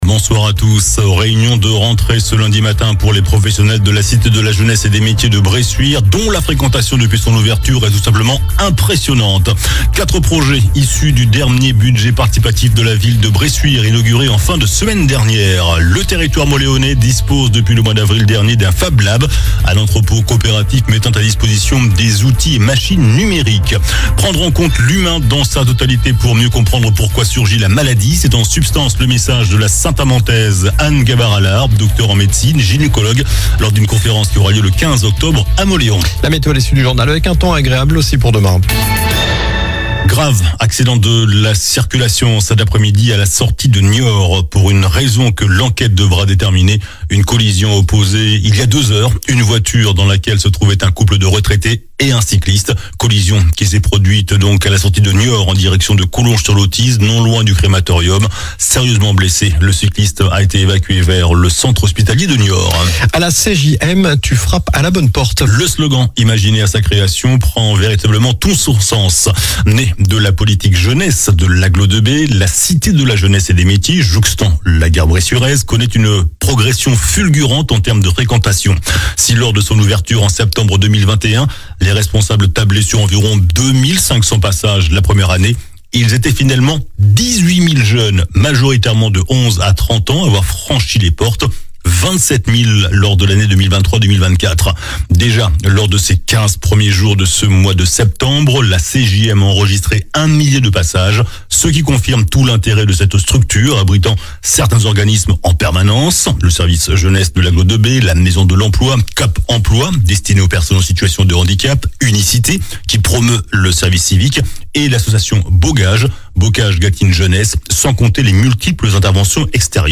Journal du lundi 16 septembre (soir)